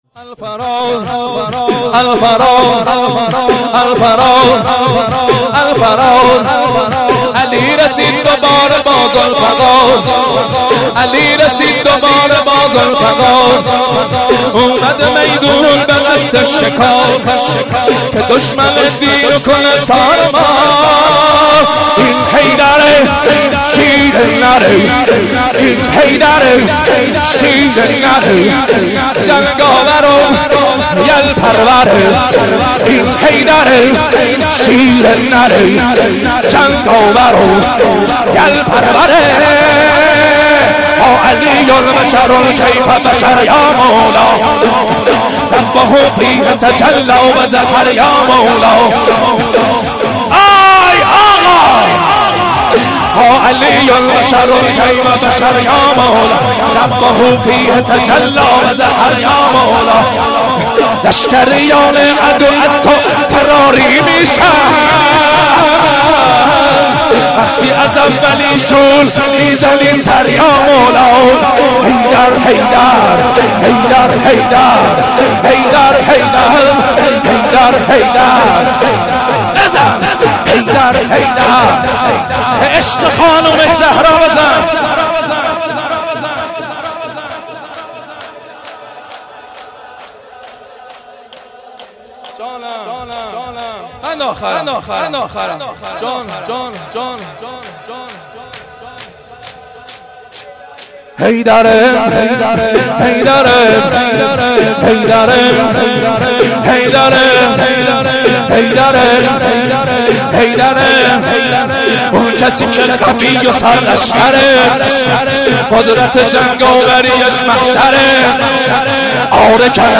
شور - الفرار علی رسیده دوباره با ذوالفقار(شورطوفانی- پیشنهاد دانلود
توسل هفتگی-روضه حضرت زهرا(س)-13 بهمن 1396